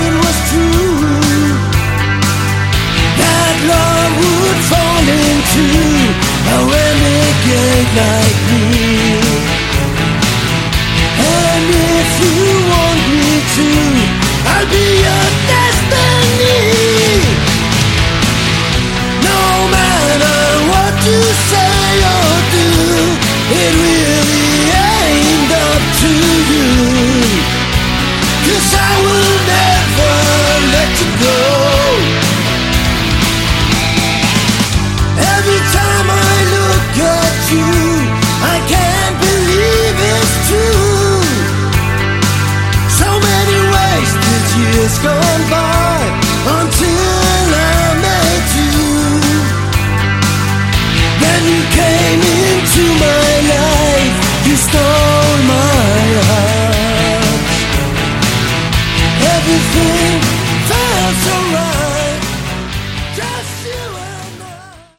Category: Hard Rock
Vocals, Bass
Guitar, Drums